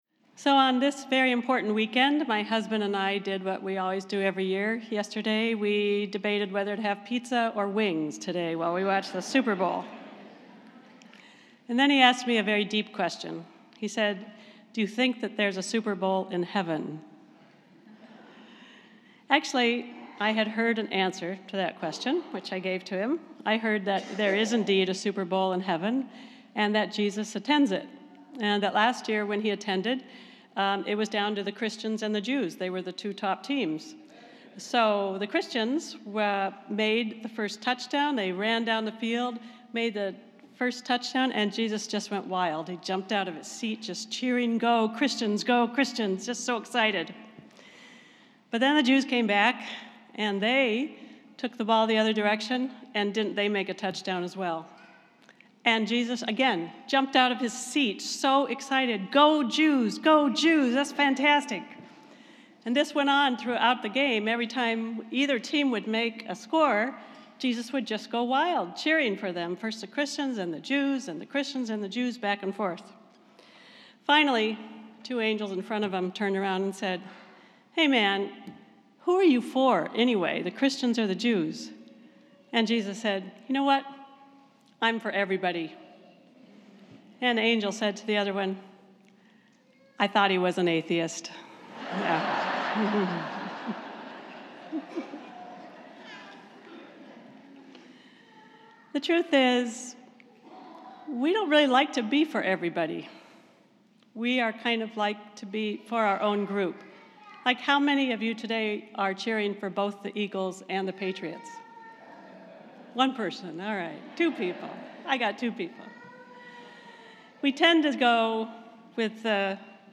This is a Spiritus Christi Mass in Rochester, NY.